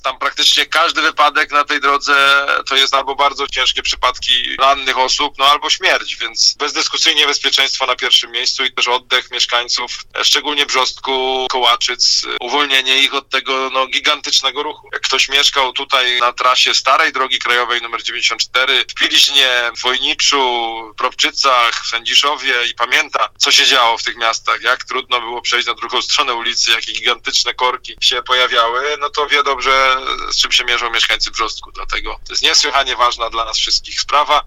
Jak mówił Radiu RDN Małopolska starosta dębicki Piotr Chęciek, wydano już decyzję środowiskową, co pozwoli na przygotowywanie kolejnych etapów.